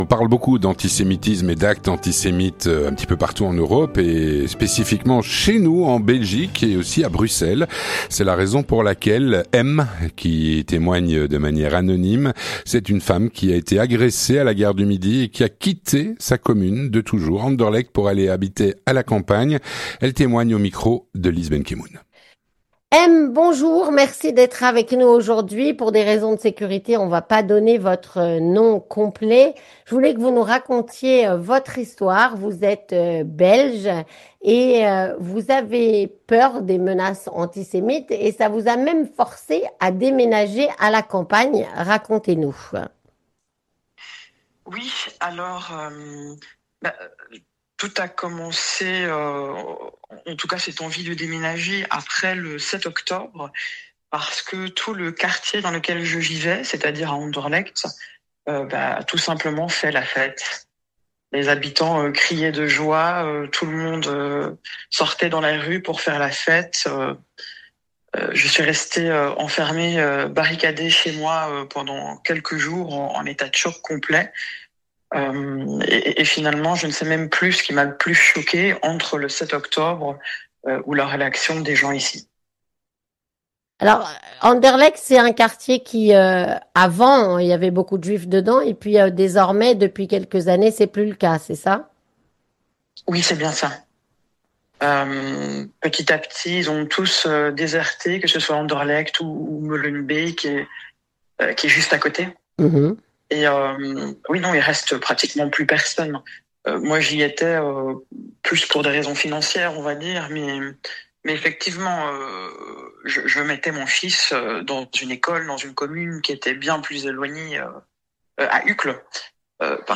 Témoignage - Une agression antisémite l'a fait quitter Anderlecht pour s'installer à la campagne.